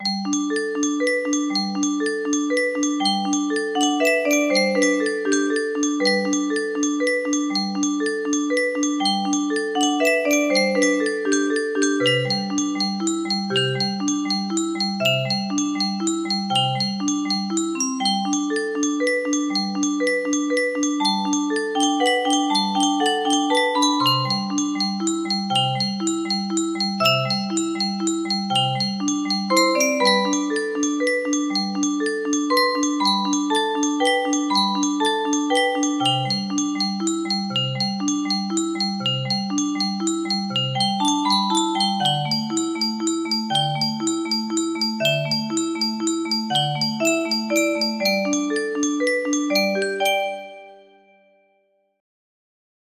Soft Piano Theme music box melody